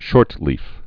(shôrtlēf)